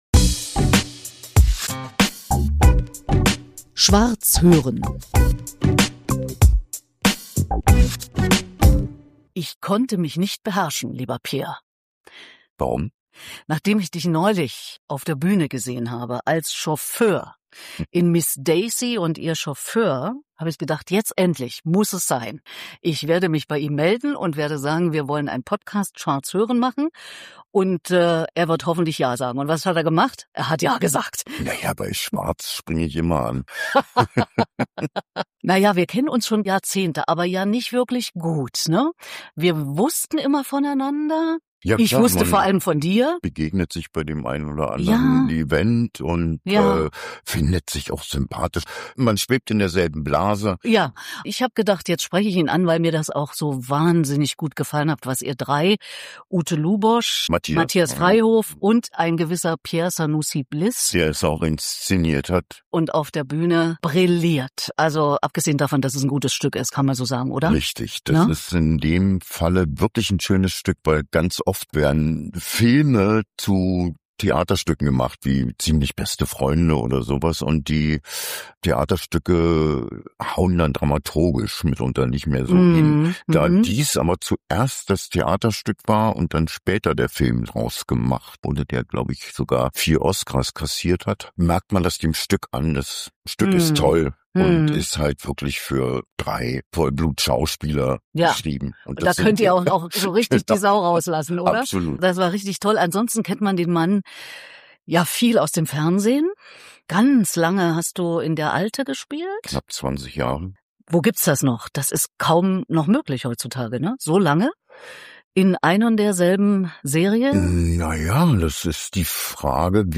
im Gespräch mit Pierre Sanoussi-Bliss über Leben und Tod